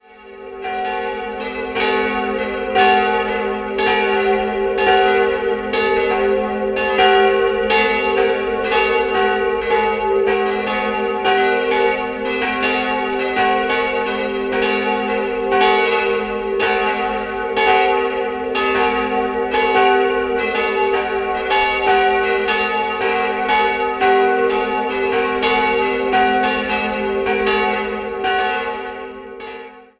Prachtvoll sind die drei spätbarocken Altäre aus der Zeit um 1750, die ursprünglich zum Kloster Geisenfeld gehörten. 3-stimmiges Gloria-Geläut: fis'-gis'-h' Die beiden kleineren Glocken wurden 1948 von Karl Hamm in Regensburg gegossen, die große von Johann Georg Sedlbauer (Straubing) im Jahr 1724.